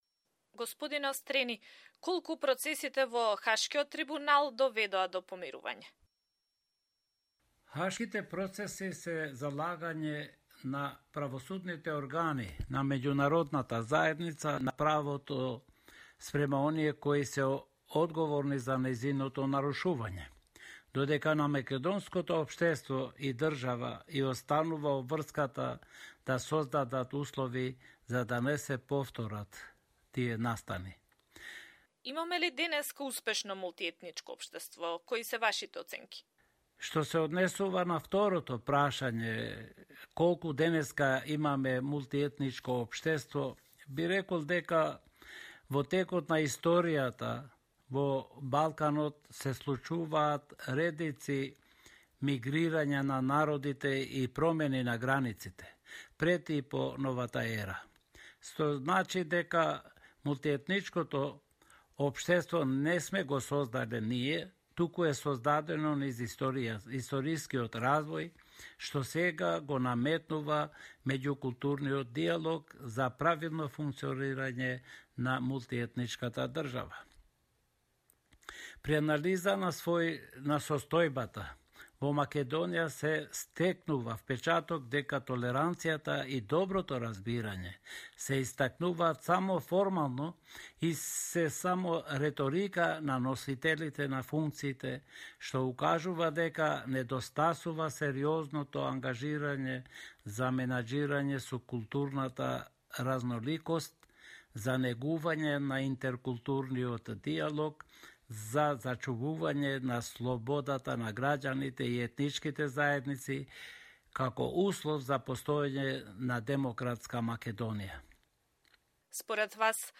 Интервју со Гзим Острени